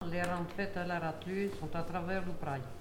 Mémoires et Patrimoines vivants - RaddO est une base de données d'archives iconographiques et sonores.
Langue Maraîchin
Catégorie Locution